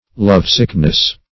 Love-sickness \Love"-sick`ness\, n. The state of being love-sick.